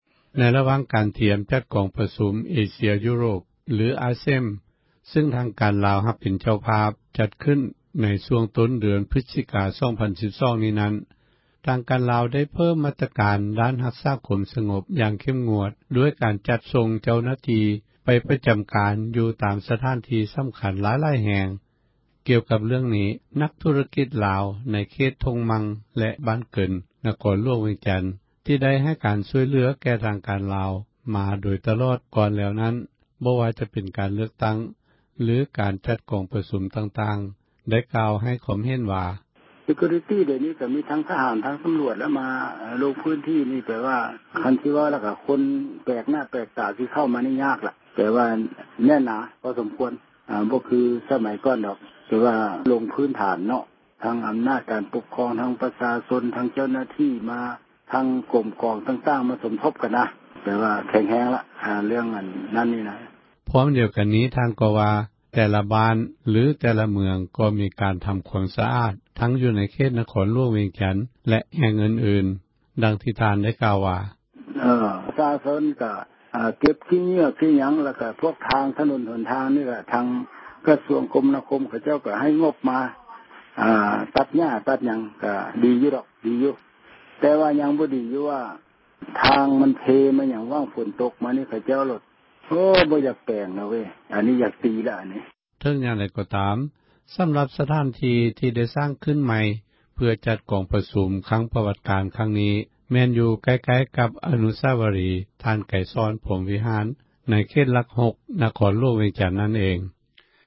ກ່ຽວກັບເຣື່ອງນີ້ ນັກທຸຣະກິຈ ລາວ ໃນເຂຕທົ່ງມັ່ງ ແລະ ບ້ານເກີນ ນະຄອນຫລວງ ວຽງຈັນ ເວົ້າວ່າ: